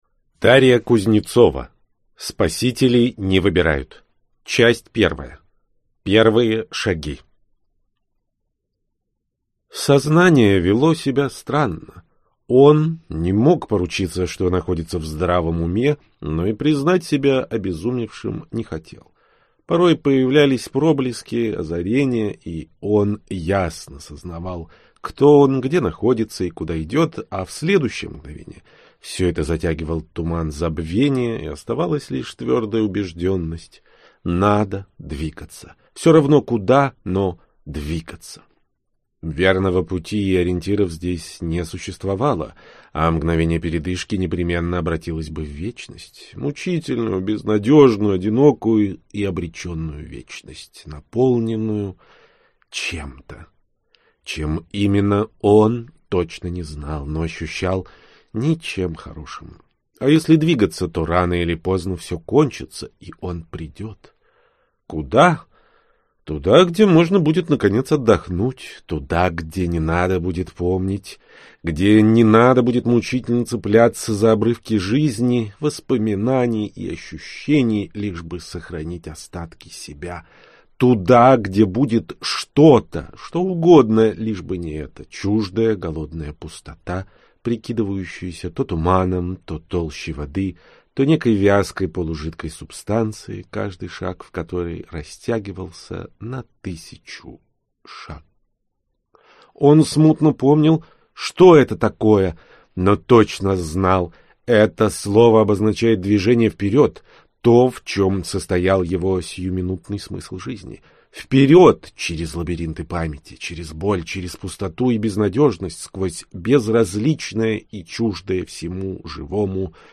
Аудиокнига Спасителей не выбирают | Библиотека аудиокниг
Прослушать и бесплатно скачать фрагмент аудиокниги